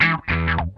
CRUNCHWAH 4.wav